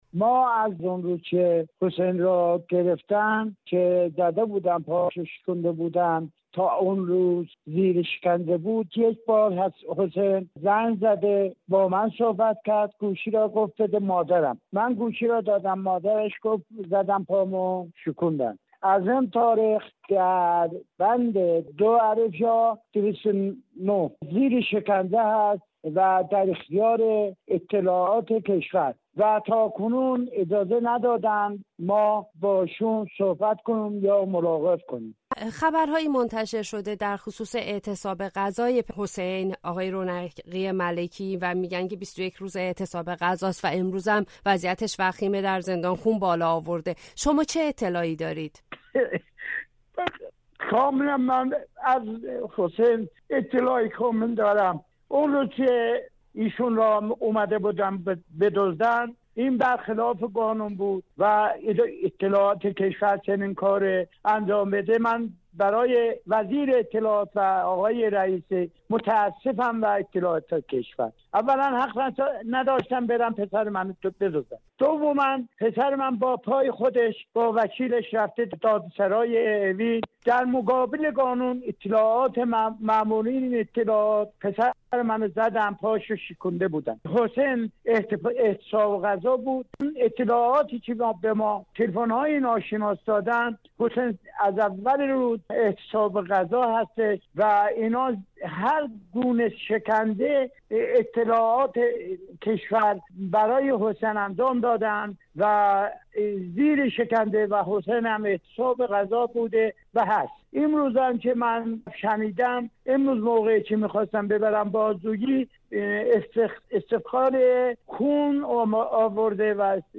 در گفتگو با رادیو فردا